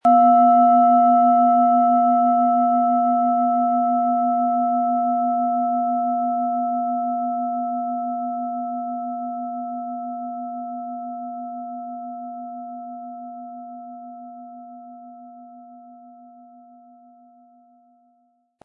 Planetenschale® Vitalisieren und gestärkt werden & Energie entfachen mit Sonne & Wasser-Ton, Ø 14,5 cm, 320-400 Gramm inkl. Klöppel
• Mittlerer Ton: Wasser
Unter dem Artikel-Bild finden Sie den Original-Klang dieser Schale im Audio-Player - Jetzt reinhören.
Durch die überlieferte Fertigung hat diese Schale vielmehr diesen außergewöhnlichen Ton und die intensive Berührung der mit Liebe hergestellten Handarbeit.